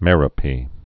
(mĕrə-pē)